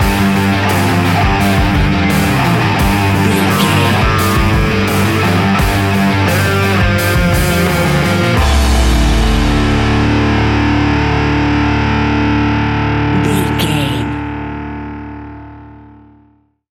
Ionian/Major
D♭
hard rock
heavy rock
distortion